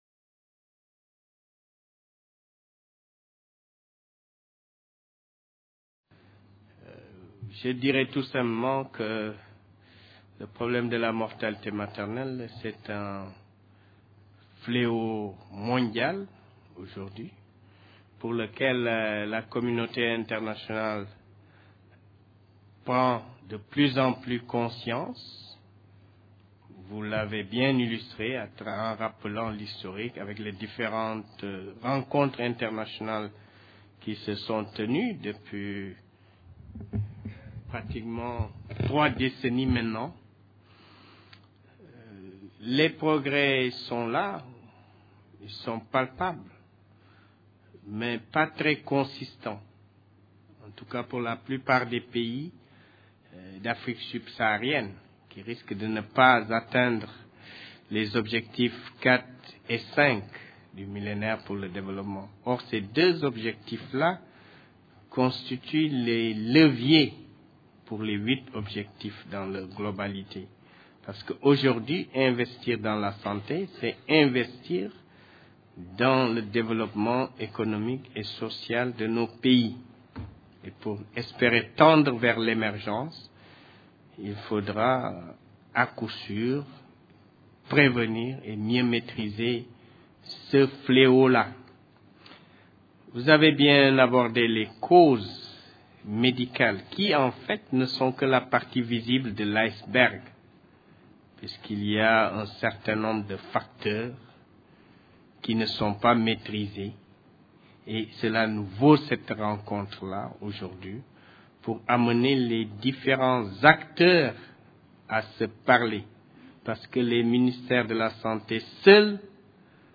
Réduction de la mortalité maternelle - Dakar 2010 : Synthèse. Conférence enregistrée dans le cadre du Colloque International Interdisciplinaire : Droit et Santé en Afrique.